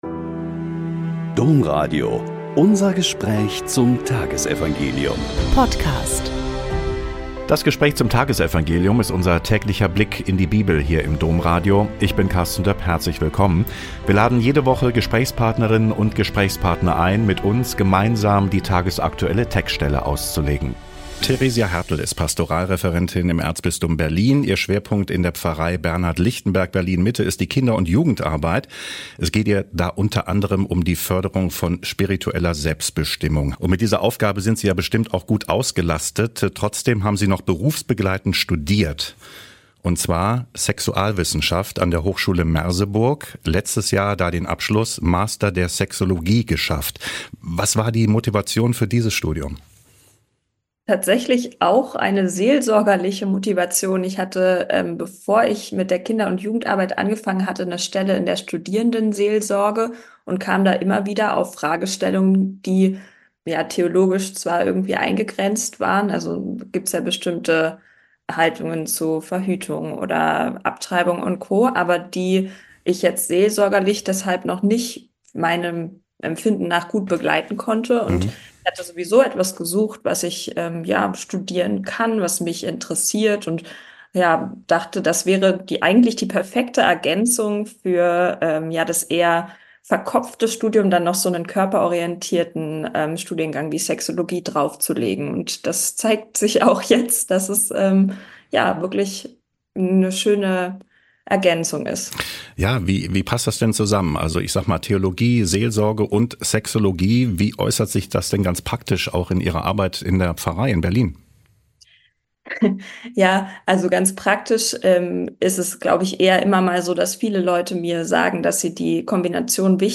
Joh 20,24-29 - Gespräch